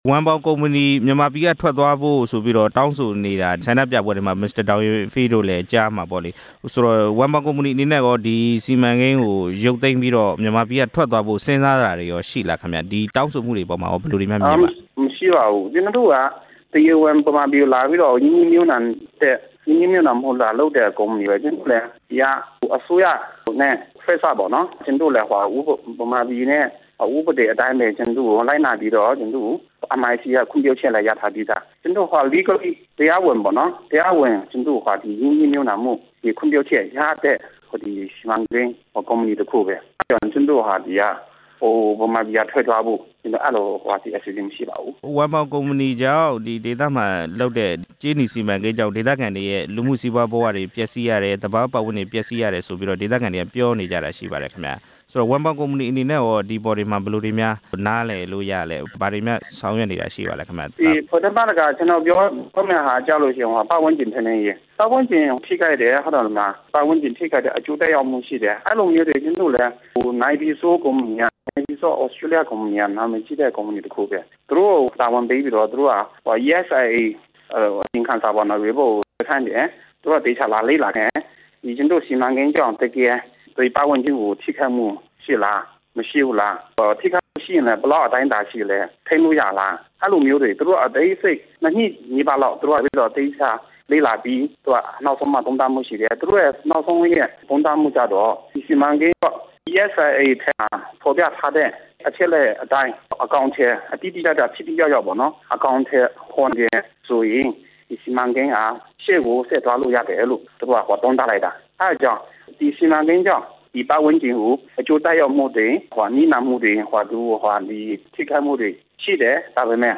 ၀မ်ပေါင်ကုမ္ပဏီနဲ့ RFA သီးသန့်ဆက်သွယ်မေးမြန်းခန်း (ဒုတိယပိုင်း)